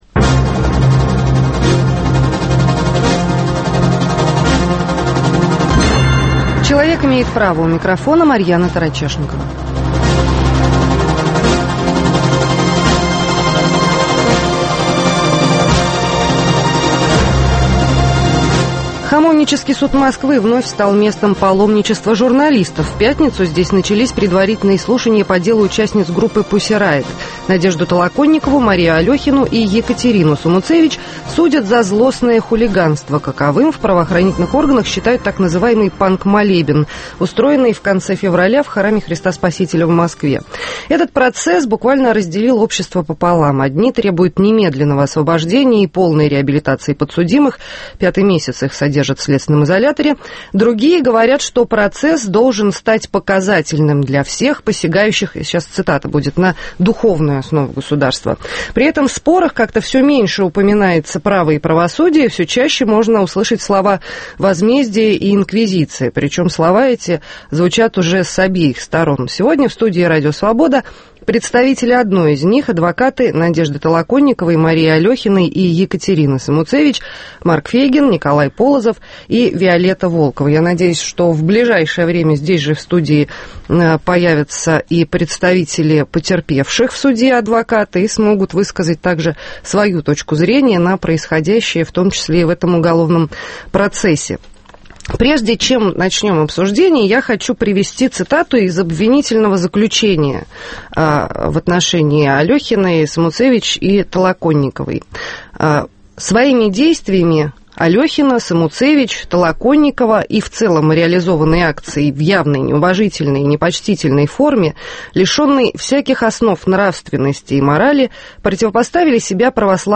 Дело Pussy Riot от правосудия к инквизиции. В студии РС